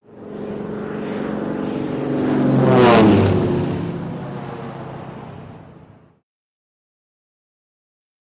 Japanese Zero|Bys
Airplane Japanese Zero By Left To Right Fast Speed Medium Perspective